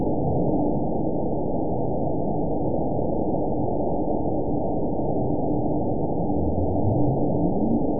event 913666 date 04/17/22 time 15:38:15 GMT (3 years, 1 month ago) score 8.97 location TSS-AB01 detected by nrw target species NRW annotations +NRW Spectrogram: Frequency (kHz) vs. Time (s) audio not available .wav